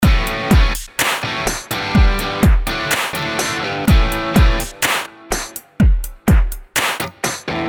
Задача - сделать фьюжн, условно, рока и дэнса, но не уходить в какой-то хардкор, брейкбит и прочие, уже хорошо представленные на рынке, штуки, а остаться в рамках uplifting коммерческой музыки.